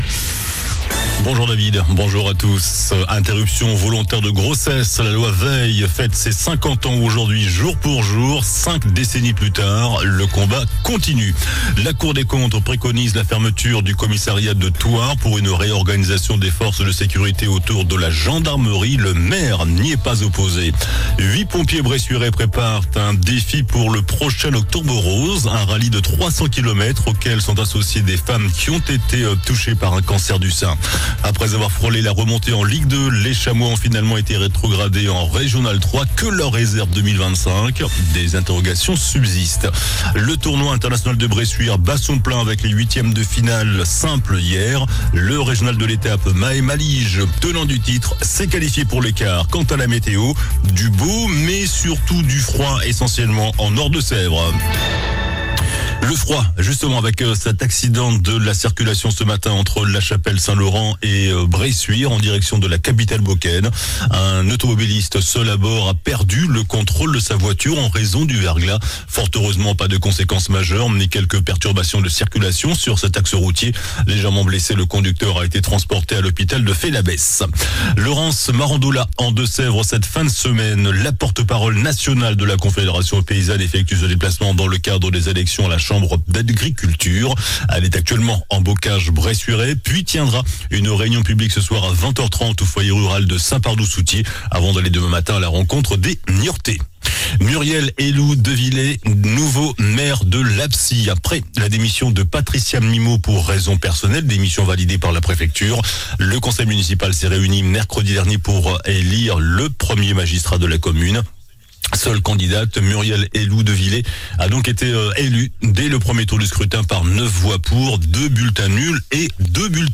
JOURNAL DU VENDREDI 17 JANVIER ( MIDI )